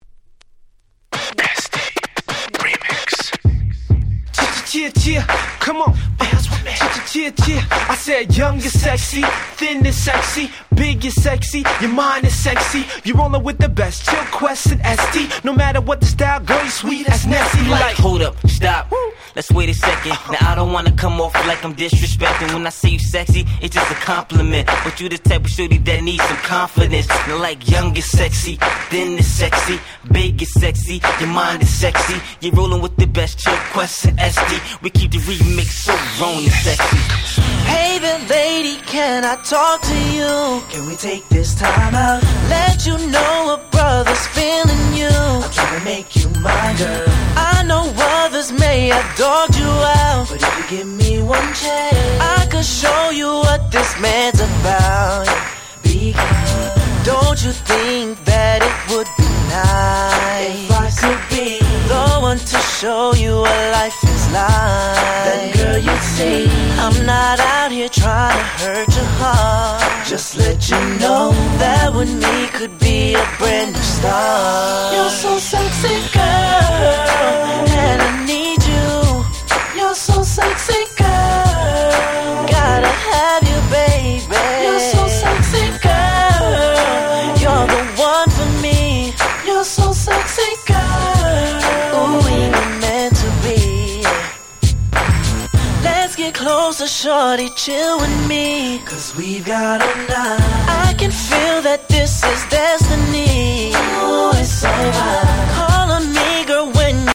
07' Nice UK R&B !!